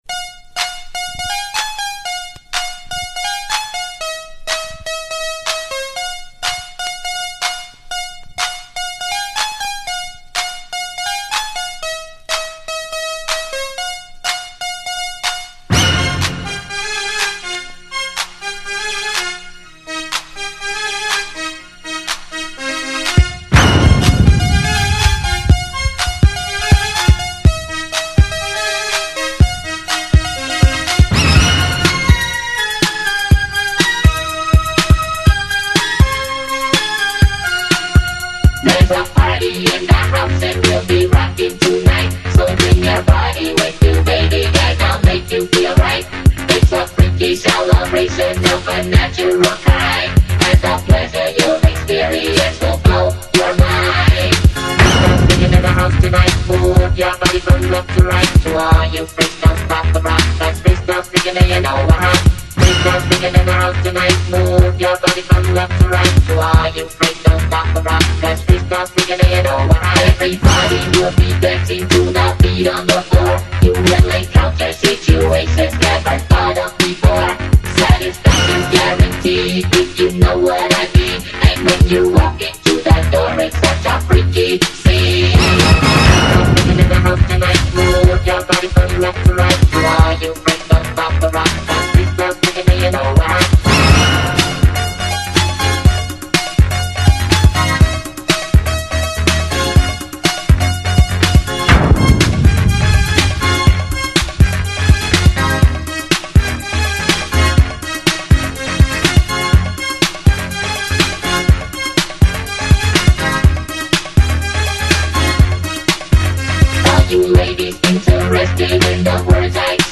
Dance Para Ouvir: Clik na Musica.